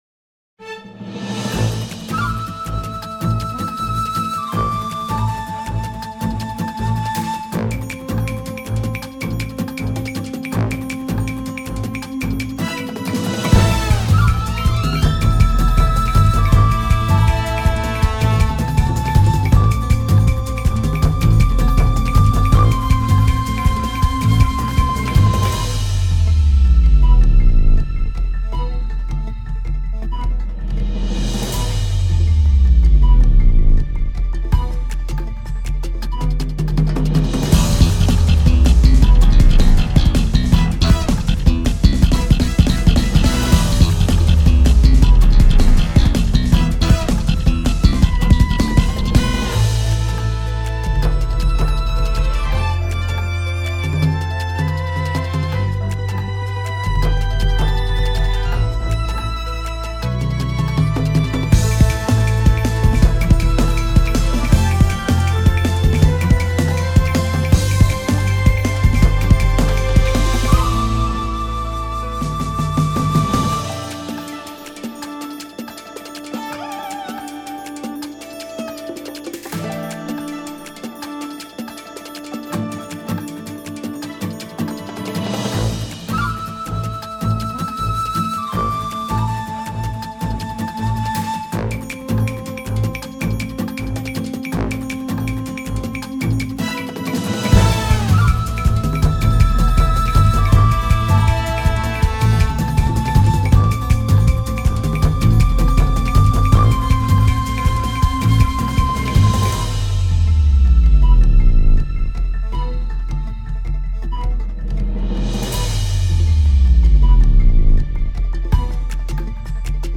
バトル中や盛り上がりのシーンなどに使えそうなかっこいい系のフリーBGMです。